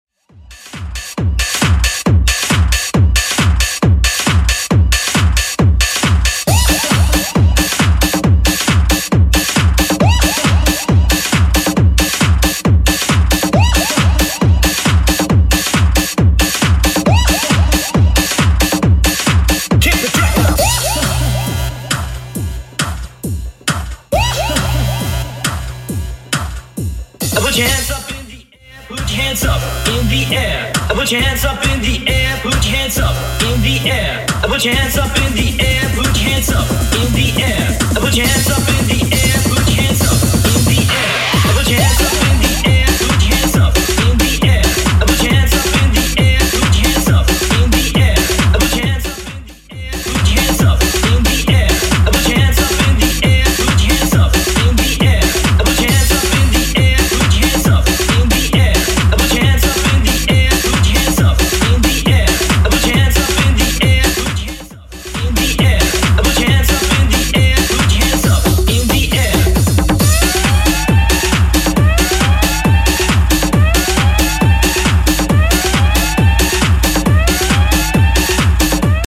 Genre: 2000's
BPM: 122